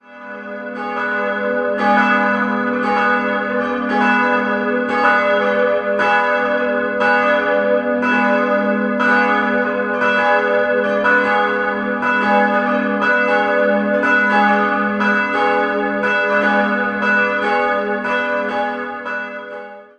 3-stimmiges Geläut: gis'-h'-d''
Die beiden größeren Glocken wurden 1981 von der Heidelberger Glockengießerei hergestellt, die kleine stammt aus dem Jahr 1911 von Ulrich Kortler aus München.